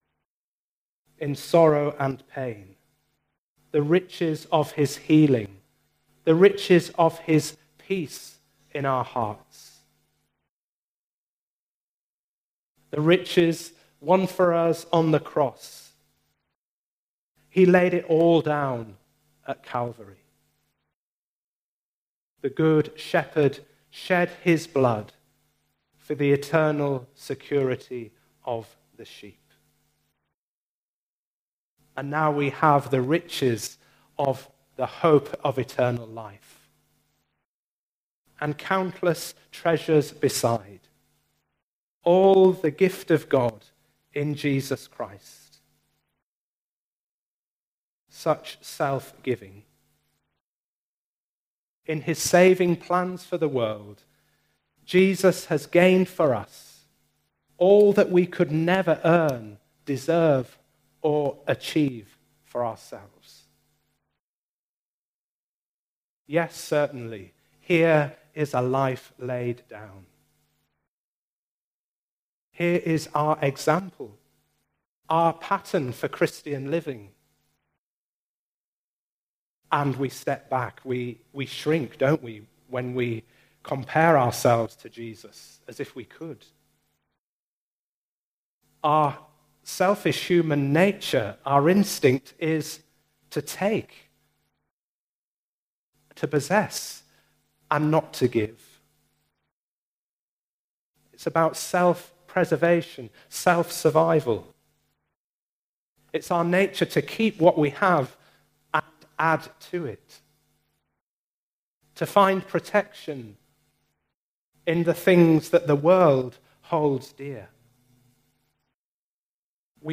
Unfortunately the first 5 minutes of this sermon were not recorded, but the main ideas can still be heard, along with all of the second part.
Service Type: Sunday Morning